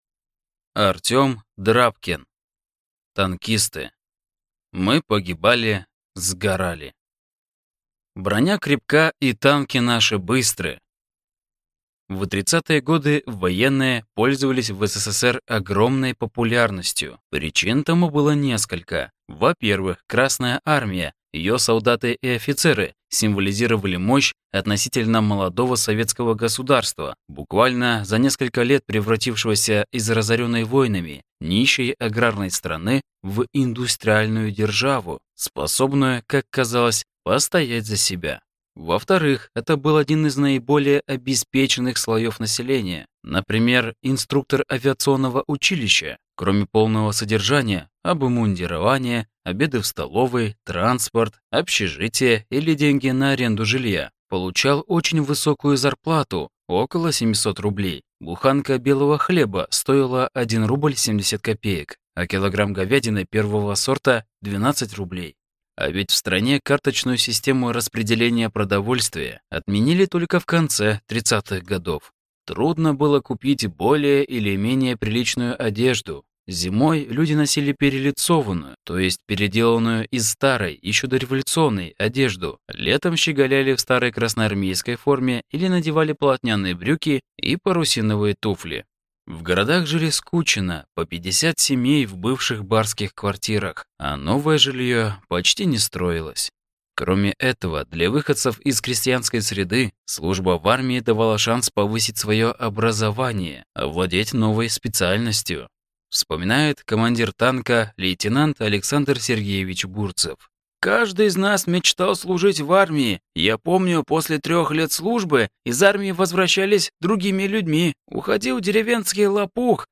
Аудиокнига Танкисты. «Мы погибали, сгорали…» | Библиотека аудиокниг